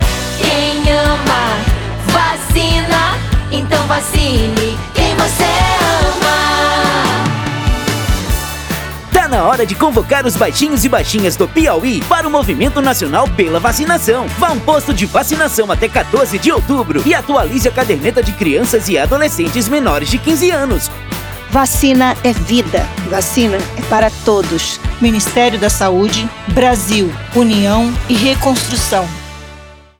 Áudio - Spot 30seg - Campanha de Multivacinação no Piauí - 1,1mb .mp3